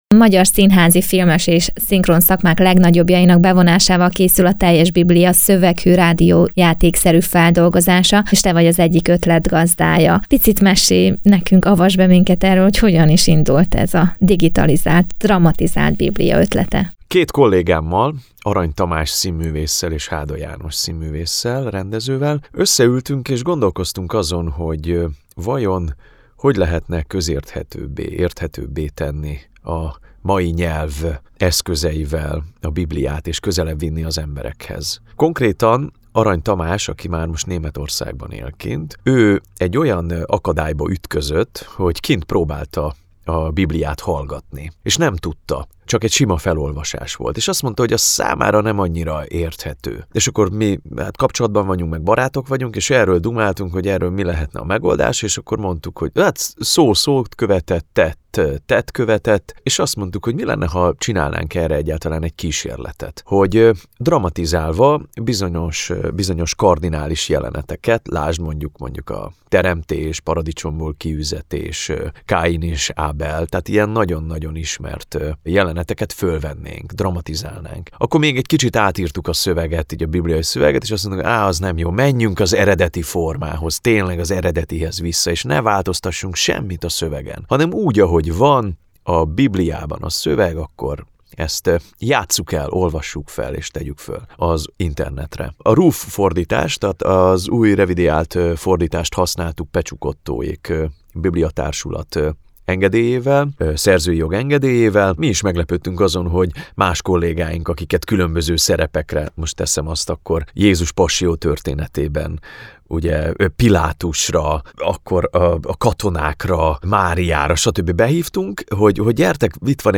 dramatizalt_biblia_zambori_s.mp3